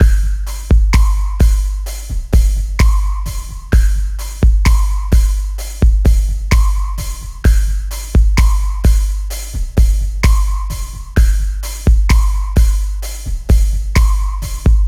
01 drums A.wav